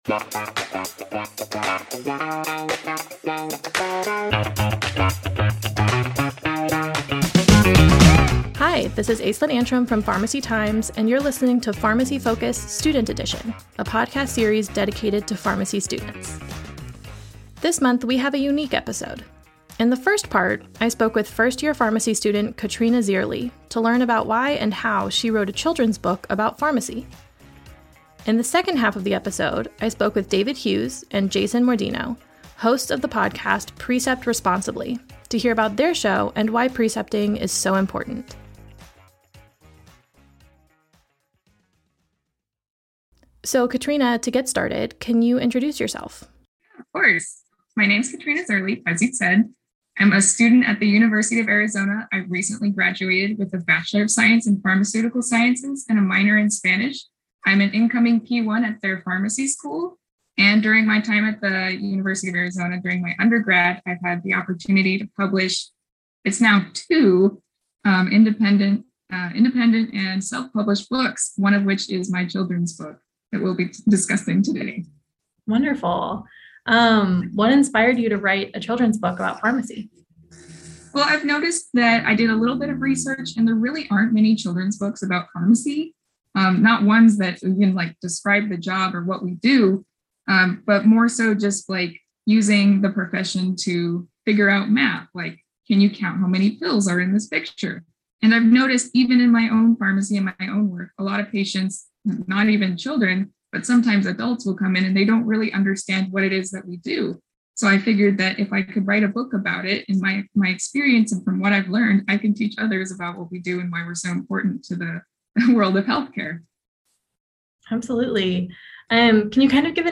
Then, listen to an interview with the hosts of the podcast Precept Responsibly and learn why current pharmacy students should start thinking about precepting early.